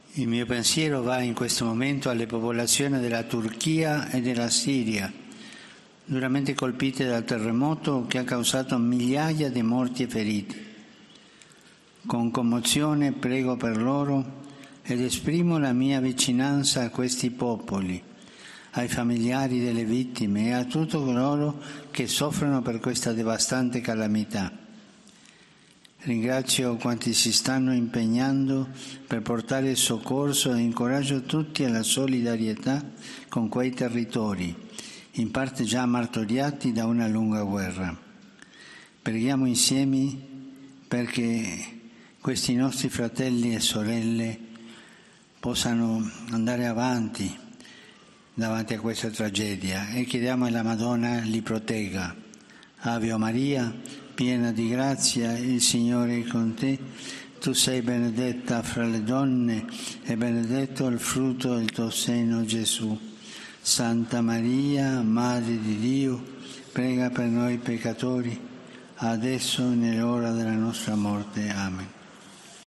Al final de la Audiencia General de este miércoles, Francisco ha dado las gracias a quienes "están prestando socorro" a todos aquellos que están sufriendo
Escucha las palabras del Papa Francisco a los pueblos de Turquía y Siria: "Pido solidaridad"